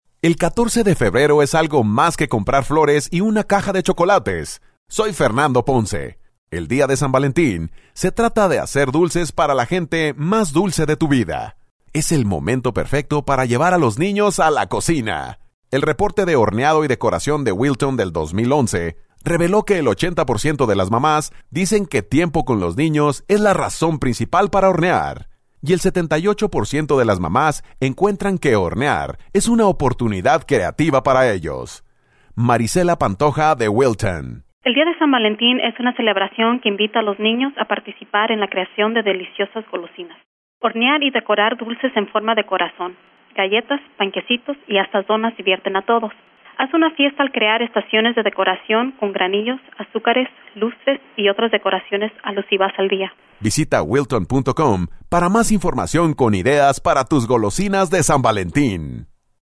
February 7, 2012Posted in: Audio News Release